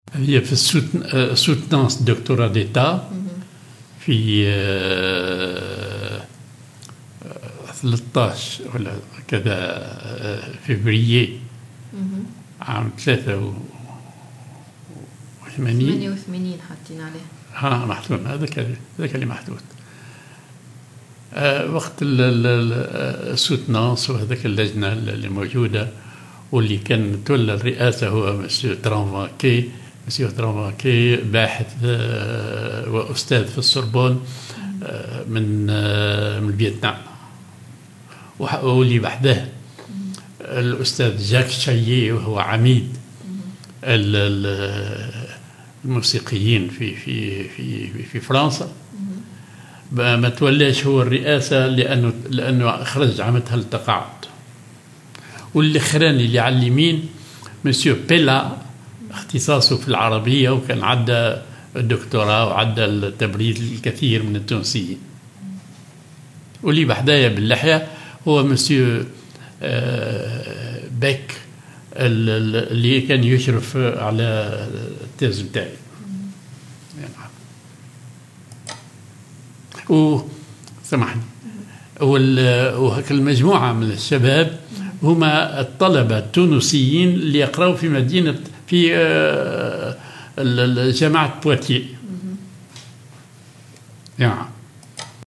Soutenance de doctorat d'état